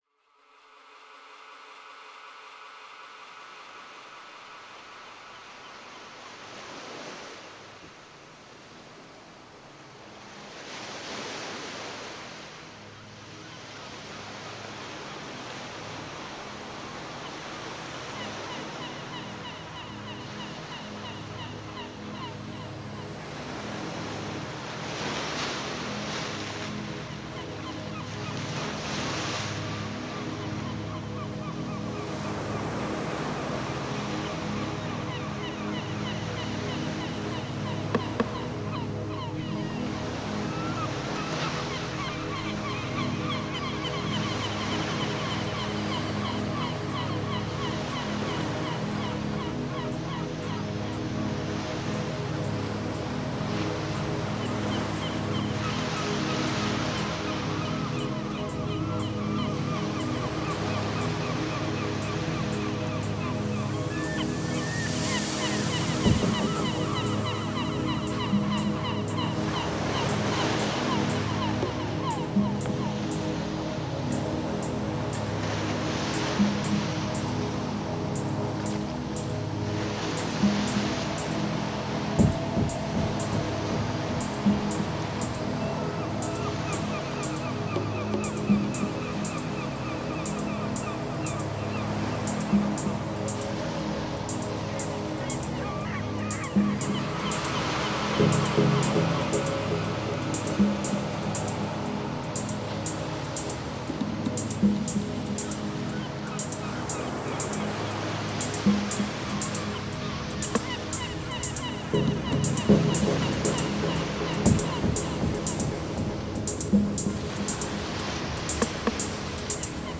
Genre: Deep Techno/Dub Techno.